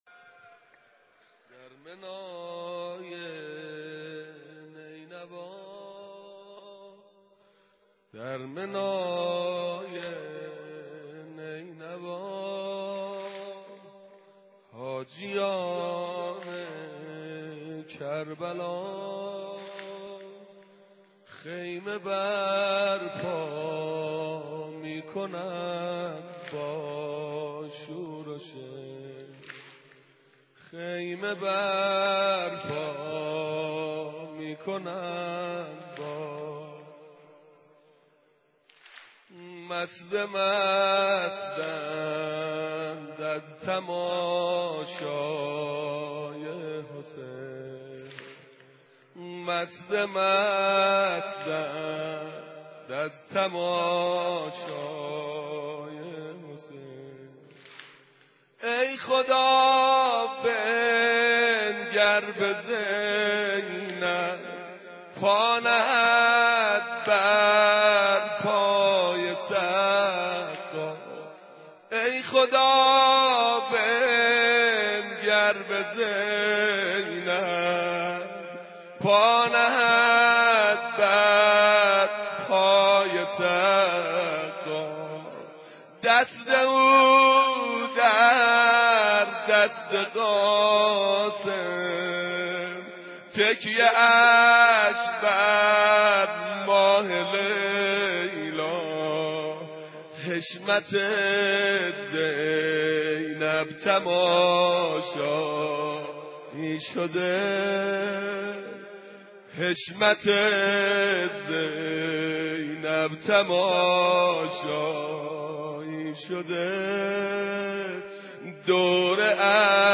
محرم روز دوم ، ورود به کربلا مداح اهل بیت استاد